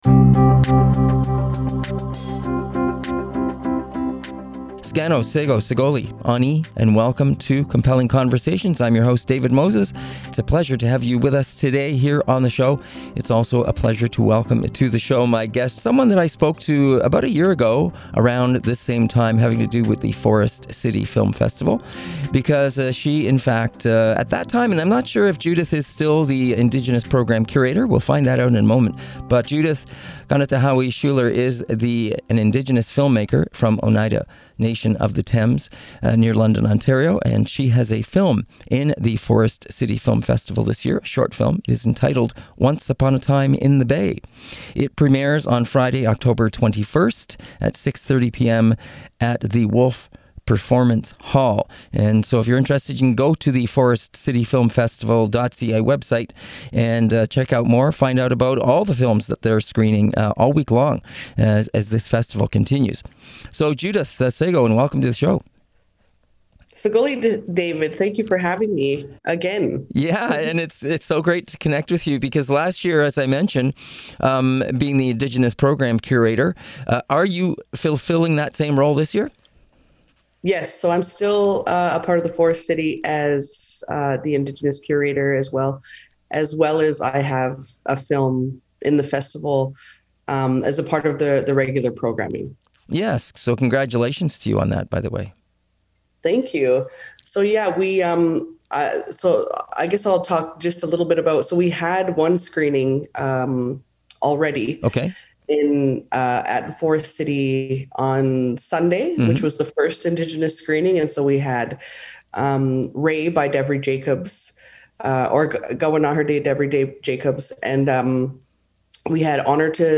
Compelling Conversation